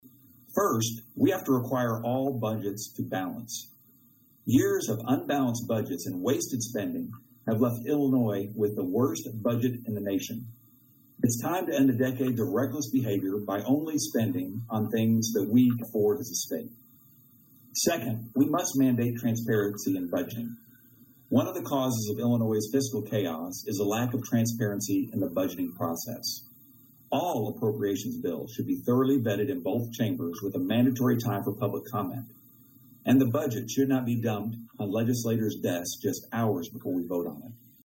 Windhorst said that responsible fiscal leadership needs to be shown in the budget making process.   Attached are comments from the Representative.